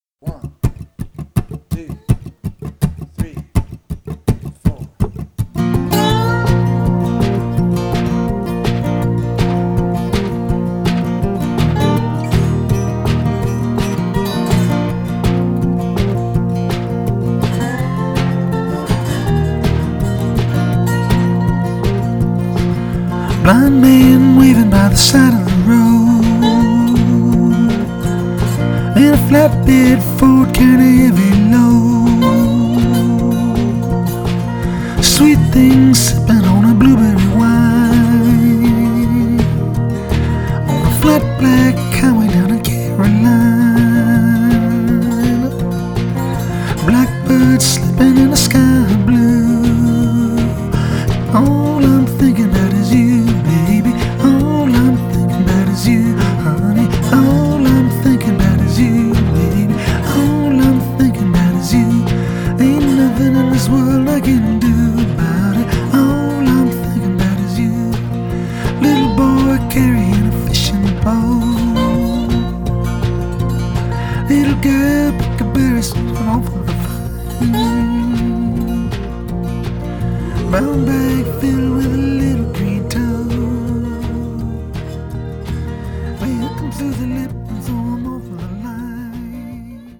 country-flavoured